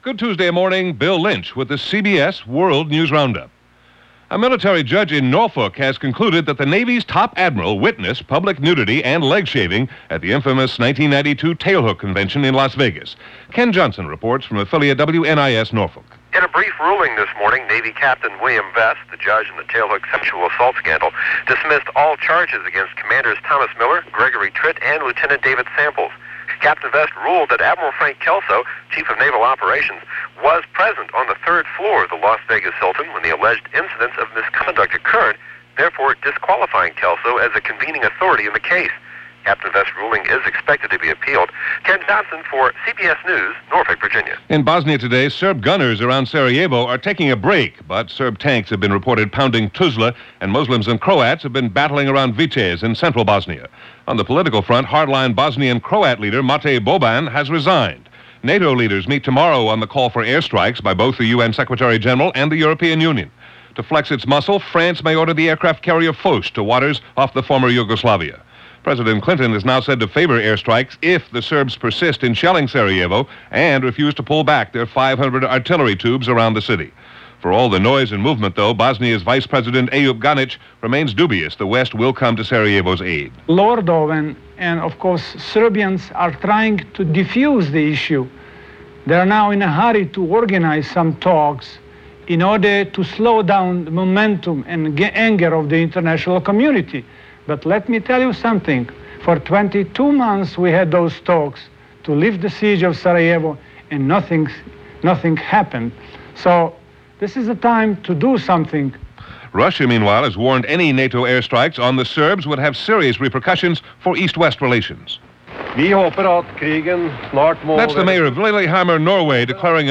– Past Daily: A Sound Archive of News, History And Music
All that, and a lot more for this February 8, 1994 as reported by The CBS World News Roundup.